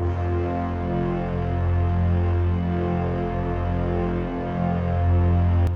piano-sounds-dev
f3.wav